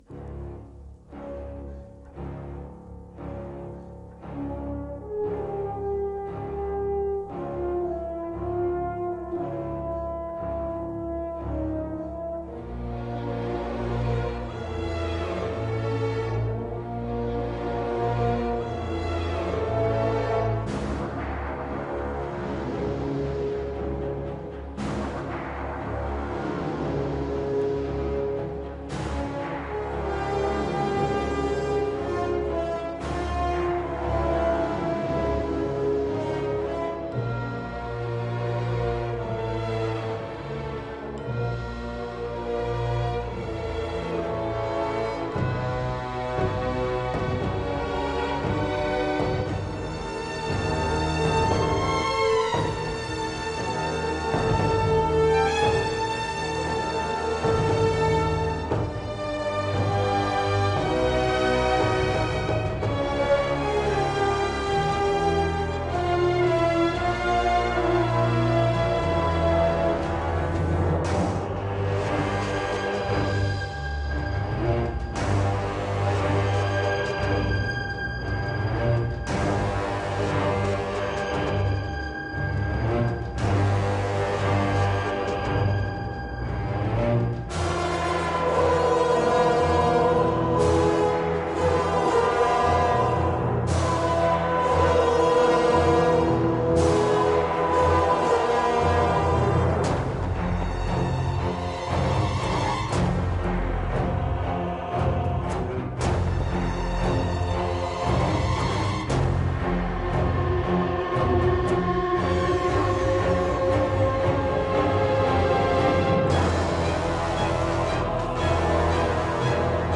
chœur psalmodiant, thérémine, orgue
Chœurs gothiques, orgue, thérémine